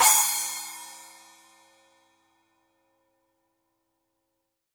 Paiste 10" PST 7 Splash Cymbal | Nicko's Drum One
The result of Paiste’s precise Swiss manufacturing is a warm and clear overall sound and by offering three weight classes.